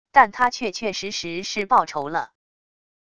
但她确确实实是报仇了wav音频生成系统WAV Audio Player